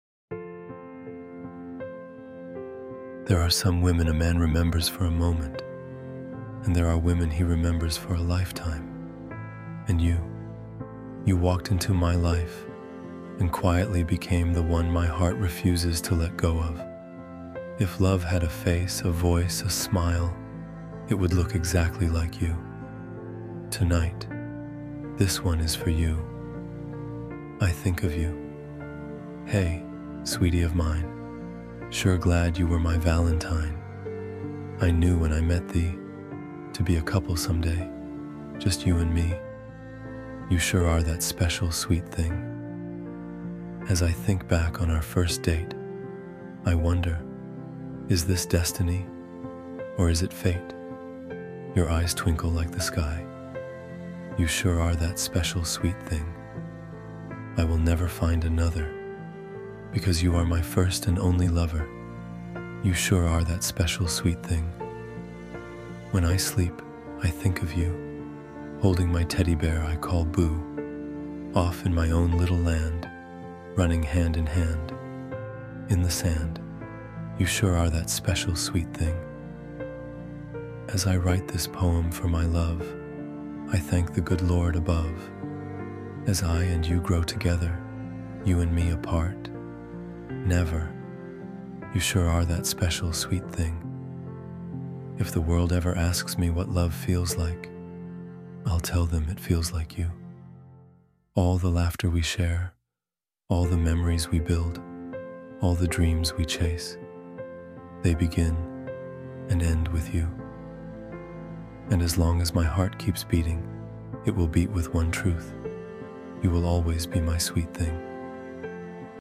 I Think of You — Romantic Love Poem for Her (Male Spoken Word) ❤ The Poem: I Think of You – A Deep Love Poem About Connection, Memory, and Timeless Devotion I Think of You Hey, sweetie of mine, Sure glad you were my Valentine.
i-think-of-you-romantic-poem-for-her-male-voice.mp3